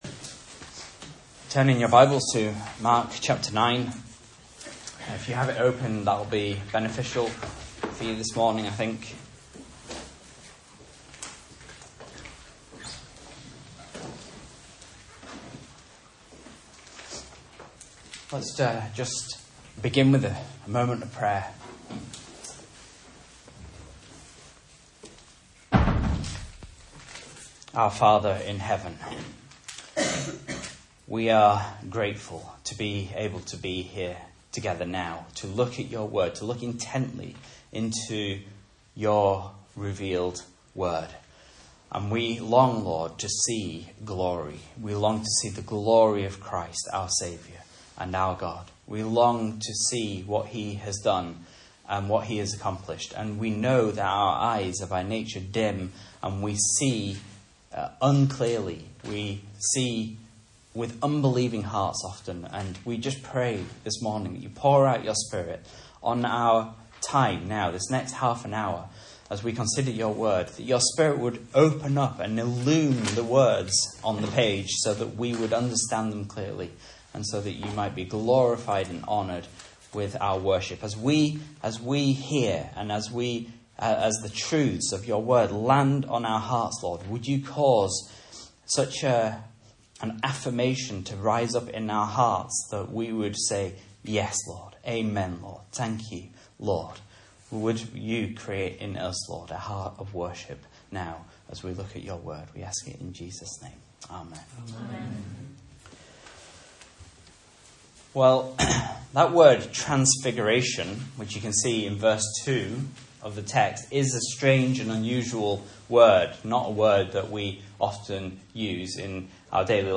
Message Scripture: Mark 9:1-13 | Listen